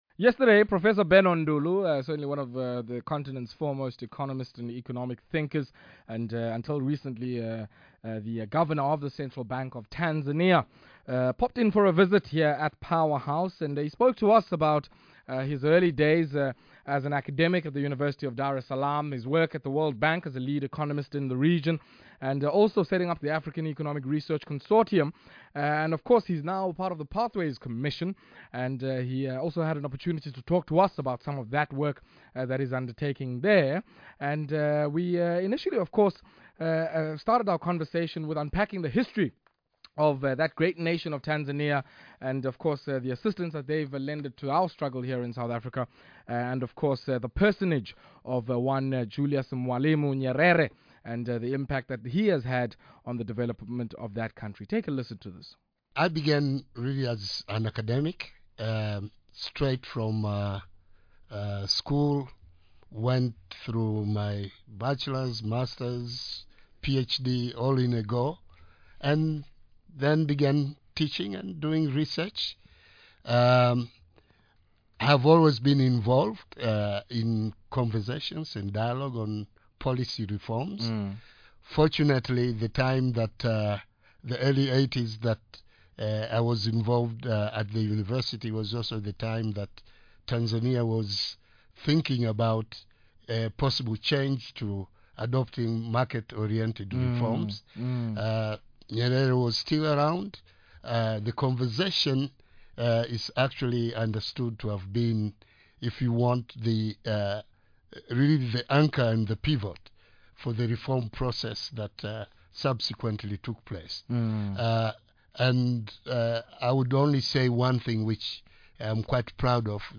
Power Business 98.7 interviews Professor Benno Ndulu | Pathways for Prosperity